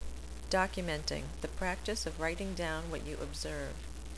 When you see the speaker speaker after each of the definitions you can hear the pronunciation of the term and what it means.